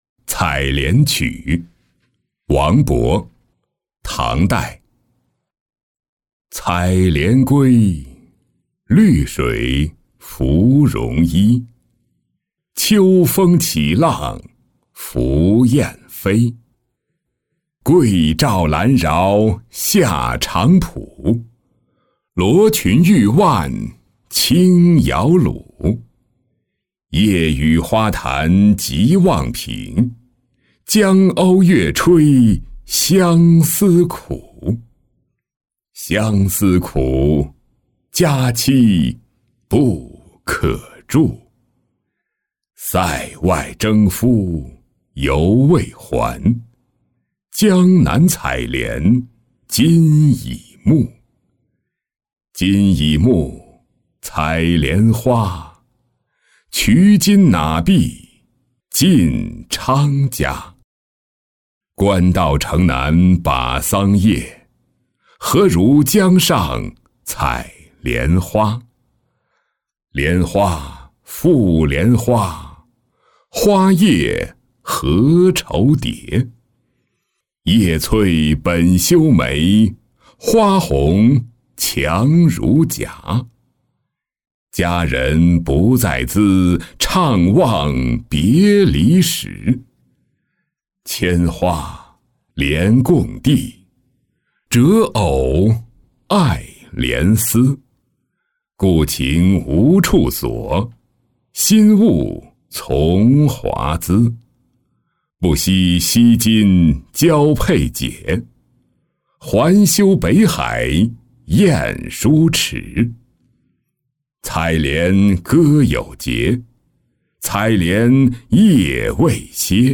登岳阳楼-音频朗读